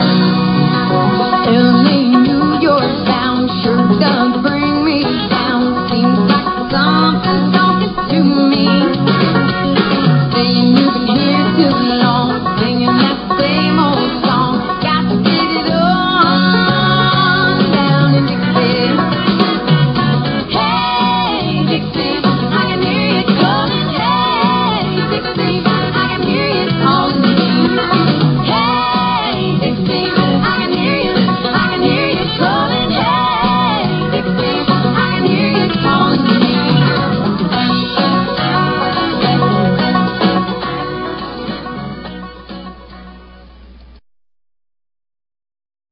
CONCERT CLIPS